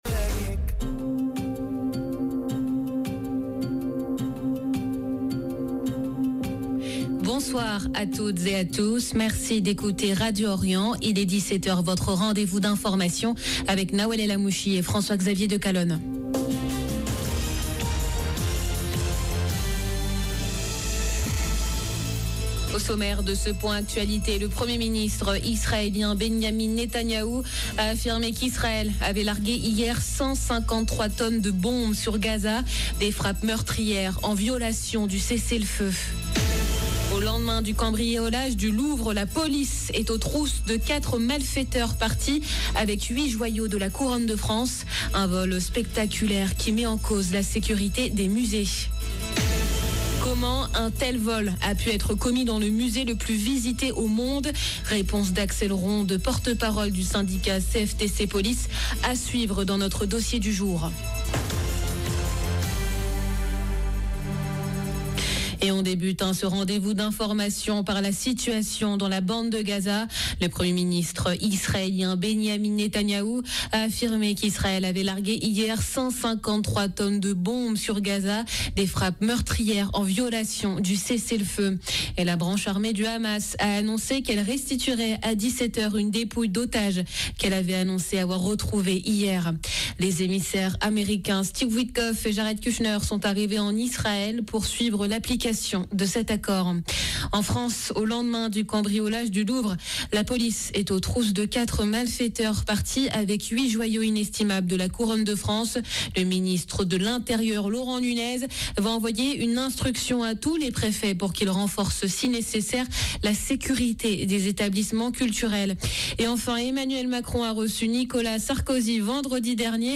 JOURANL DE 17h DU 20/10/2025